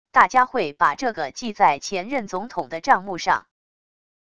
大家会把这个记在前任总统的账目上wav音频生成系统WAV Audio Player